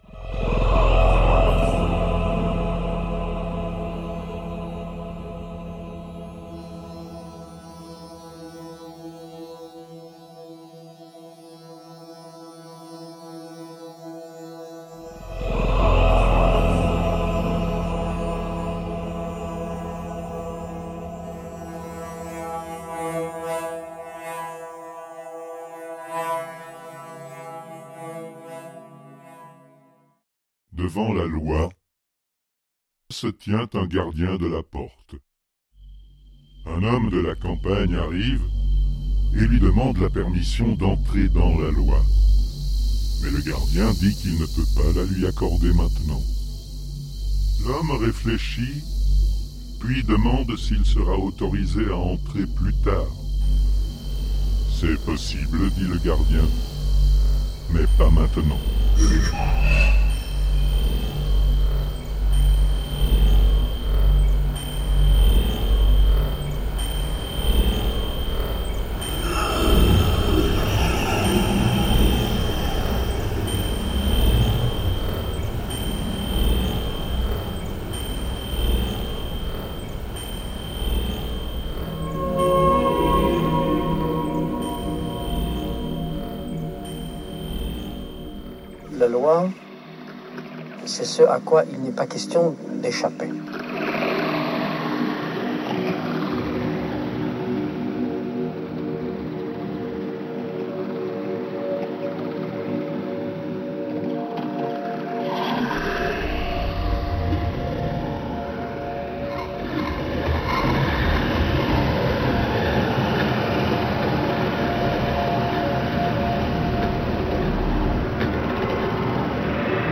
All three are “electroacoustic melodramas”